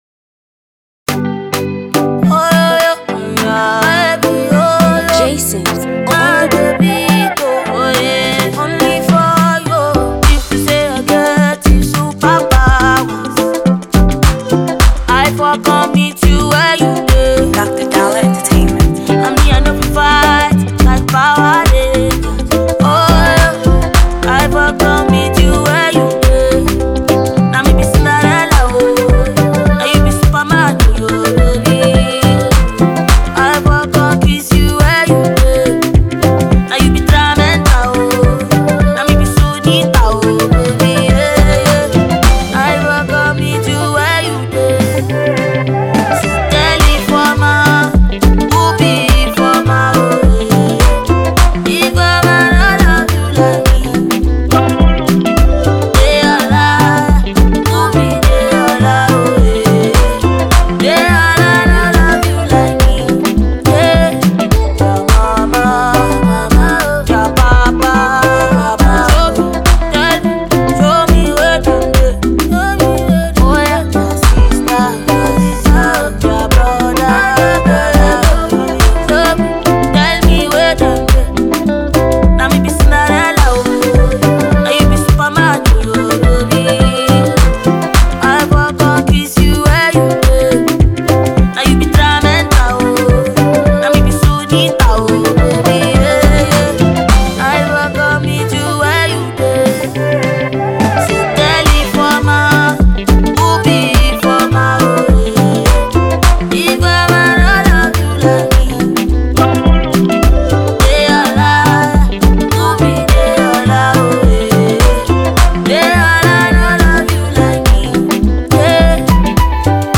Afro-pop
utilizes has sonorous tenor voice to create a solid track